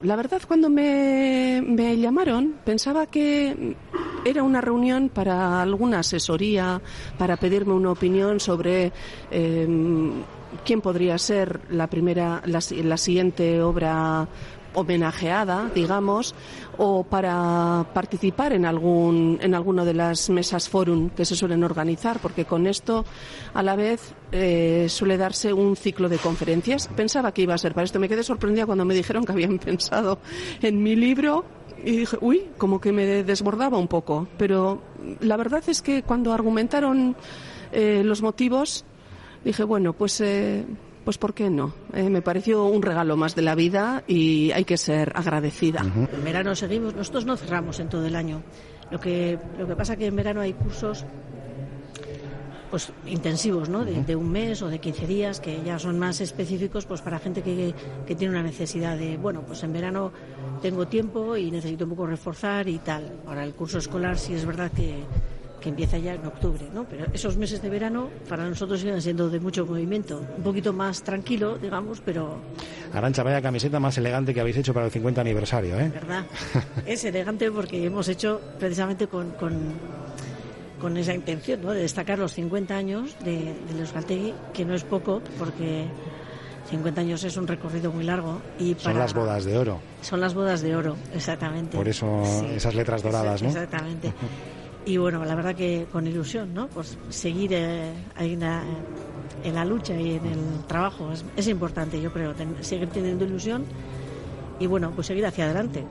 El foyer del Teatro Arriaga de Bilbao ha acogido esta mañana la lectura ininterrumpida de la obra de autoficción 'Kristalezko begi bat', de la escritora vizcaína Miren Agur Meabe.
Onda Vasca tampoco podía faltar a esta cita, por lo que hemos reunido ante nuestros micrófonos a los principales protagonistas del evento.